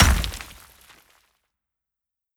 Hit_Concrete 01.wav